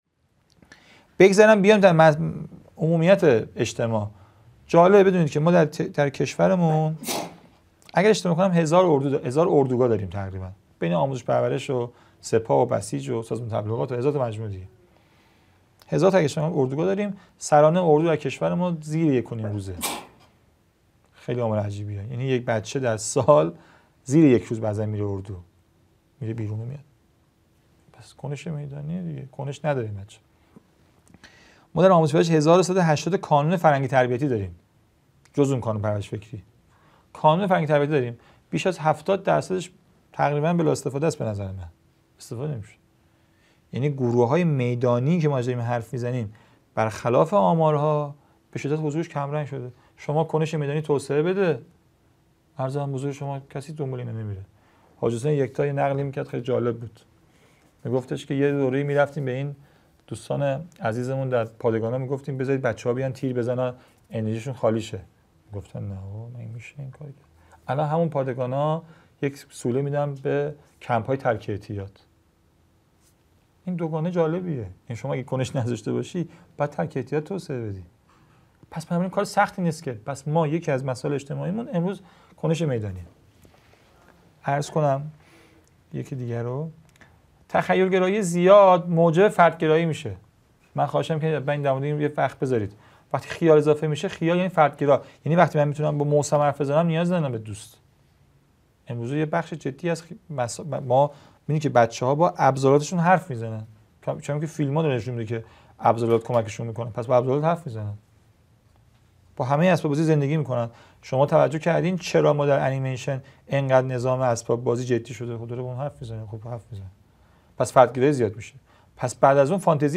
مدرسه عالی هیأت | گزیده دوازدهم از دومین سلسله نشست‌ های هیأت و نوجوانان - با موضوعیت نوجوان در ایران
قم - اردبیهشت ماه 1402